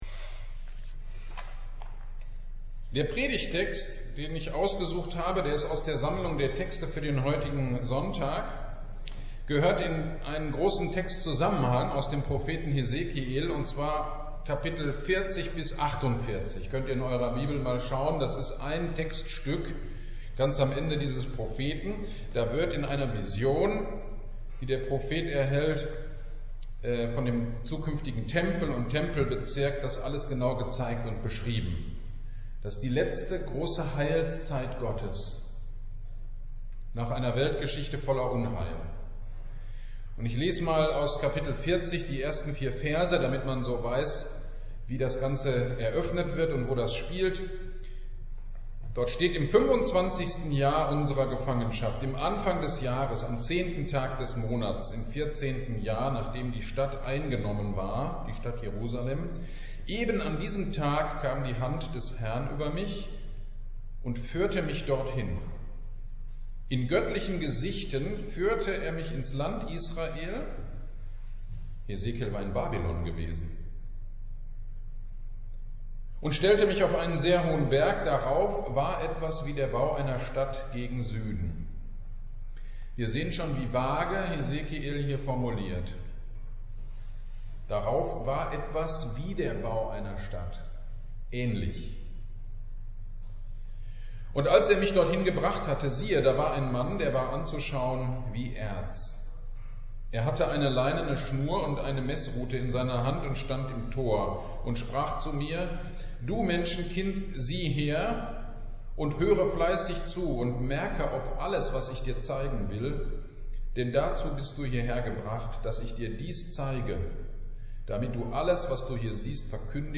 GD am 08.05.22 Predigt: Hesekiel 47. 1-12